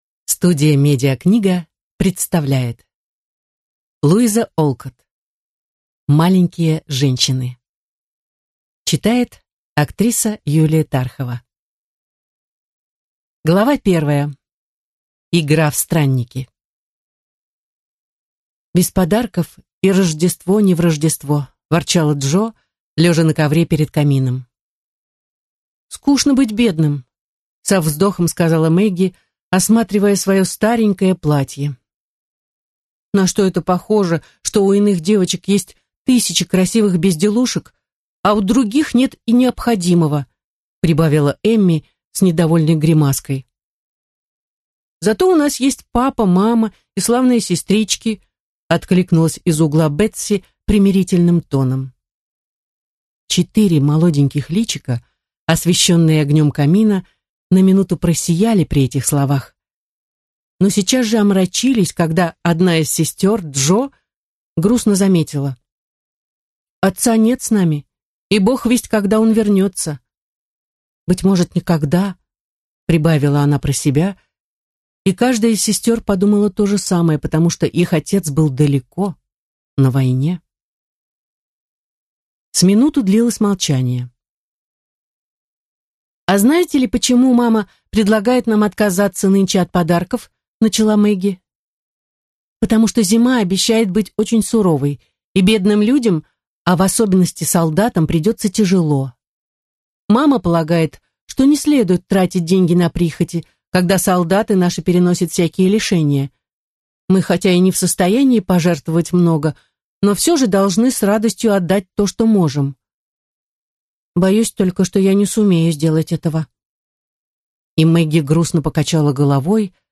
Аудиокнига Маленькие женщины | Библиотека аудиокниг